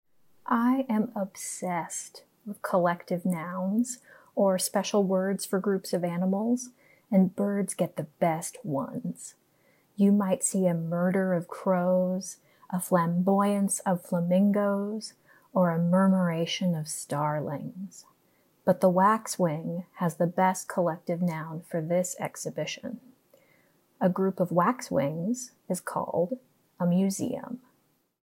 Listen to a curator talk about this work.